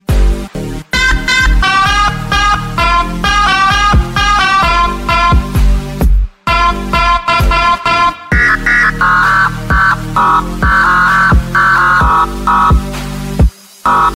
Meme Sound Effects
u3-Skibidi-Siren-Head.mp3